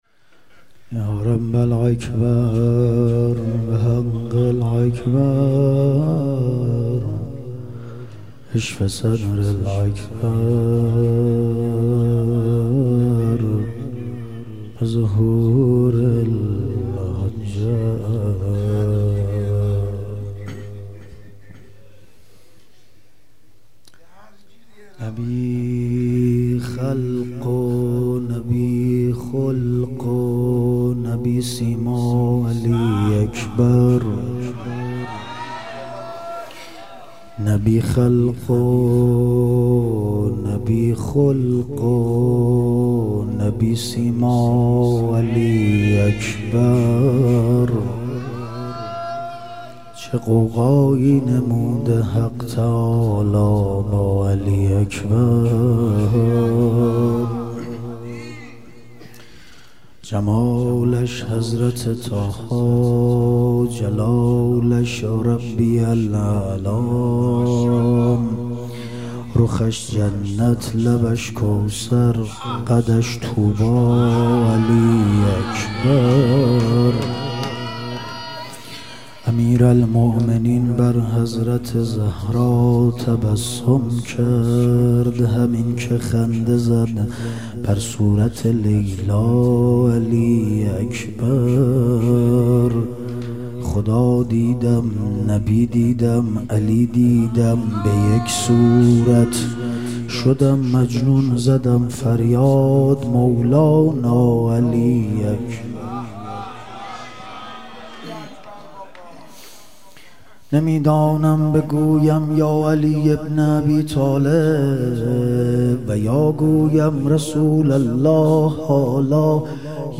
ظهور وجود مقدس حضرت علی اکبر علیه السلام - مدح و رجز